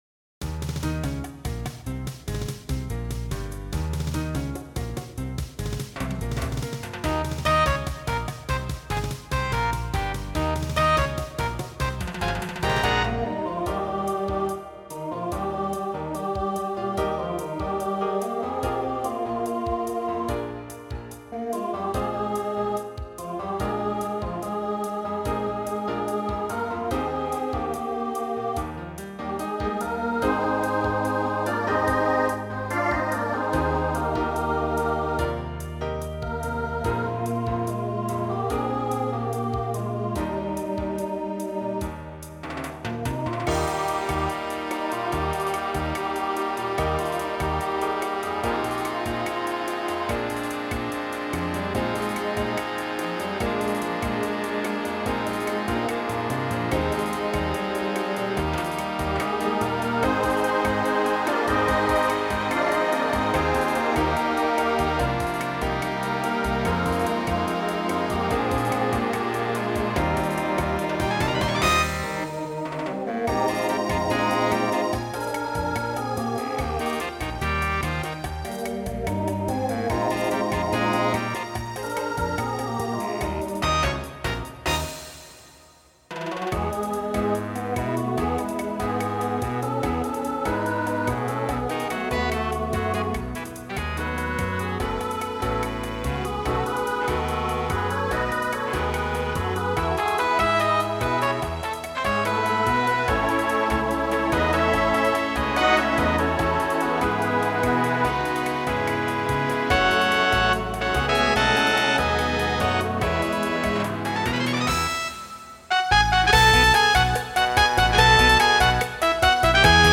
Voicing SATB Instrumental combo Genre Swing/Jazz
Mid-tempo